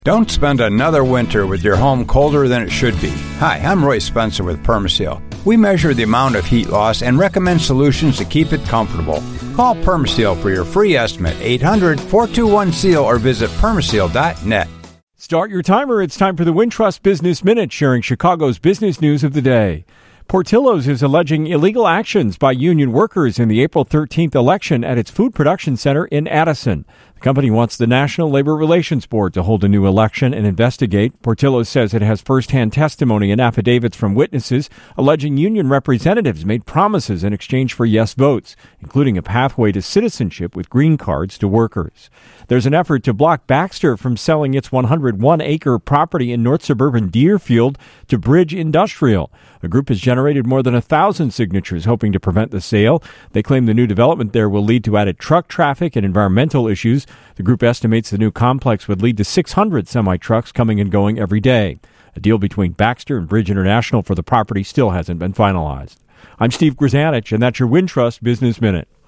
the business news of the day